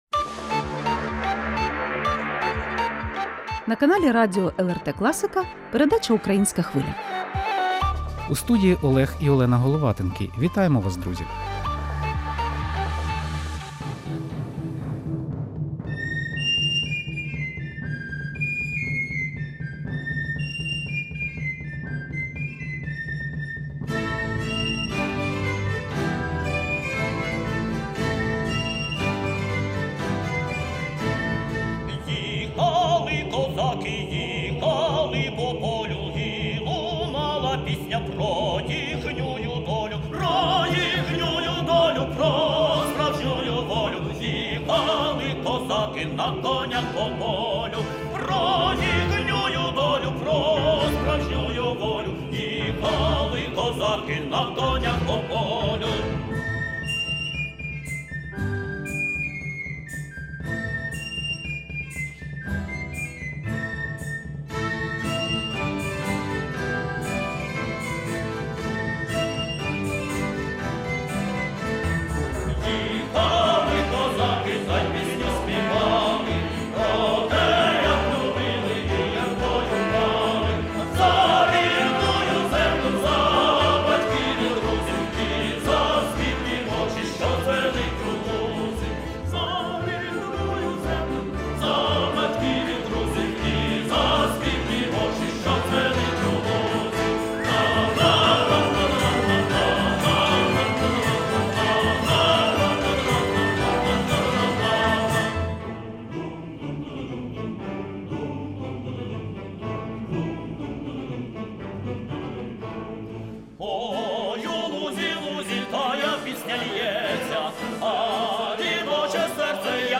Про діяльність колективу під час повномасштабної війни, актуальний репертуар, допомогу фронту, особливості благодійних міжнародних гастролей - в ексклюзивному інтерв'ю